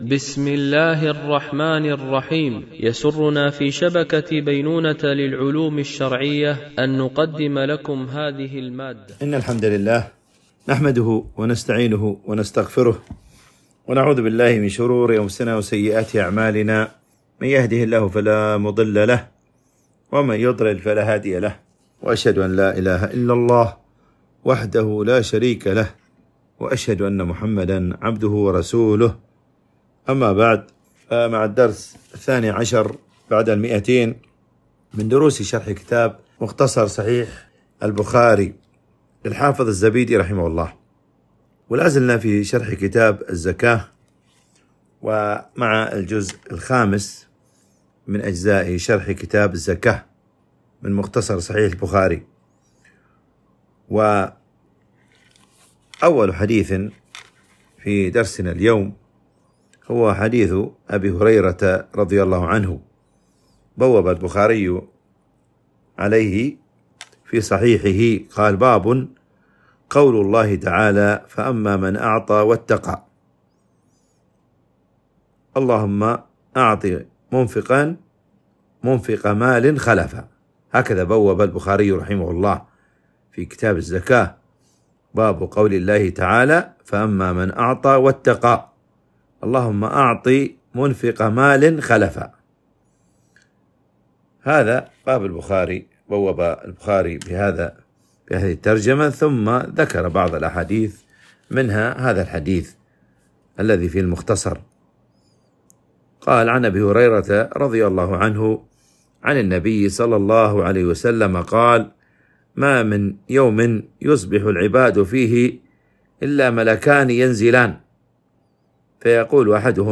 شرح مختصر صحيح البخاري ـ الدرس 212 ( كتاب الزكاة ـ الجزء الخامس - الحديث 728 - 735 )